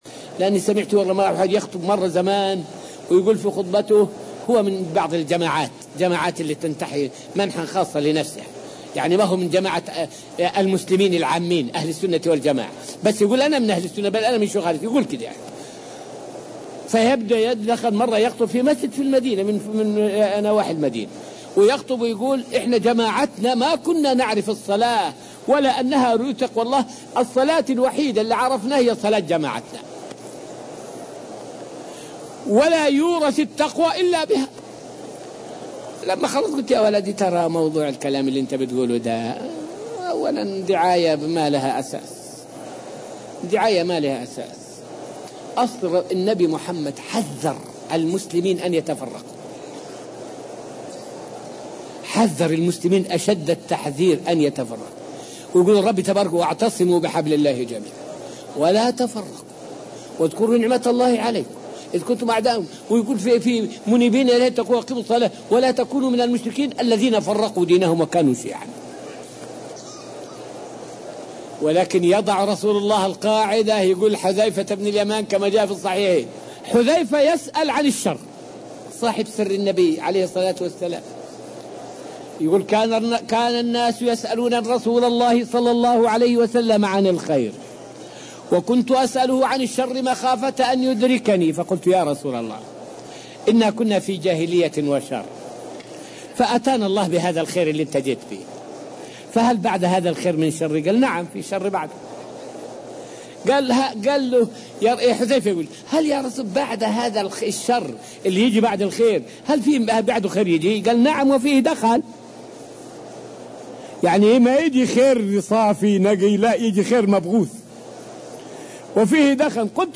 فائدة من الدرس الثامن والعشرون من دروس تفسير سورة البقرة والتي ألقيت في المسجد النبوي الشريف حول تفسير قوله تعالى {فباشروهن وابتغوا ما كتب الله لكم}.